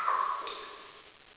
drip3.wav